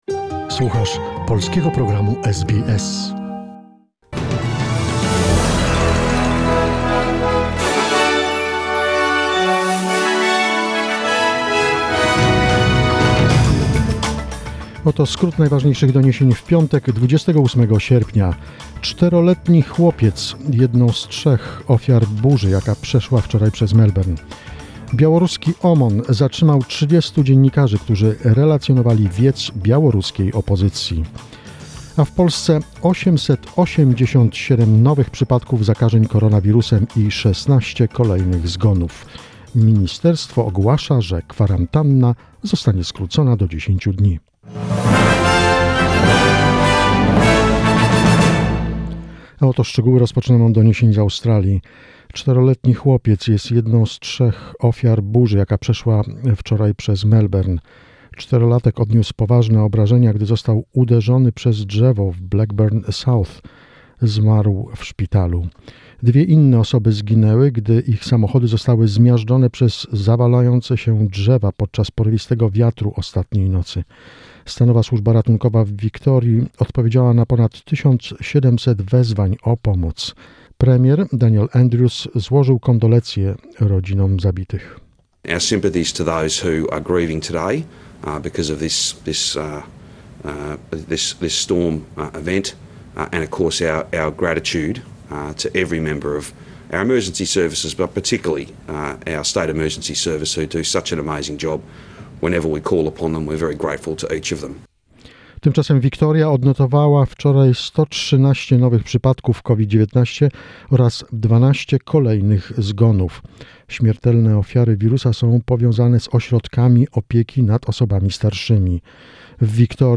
SBS News, 28 August 2020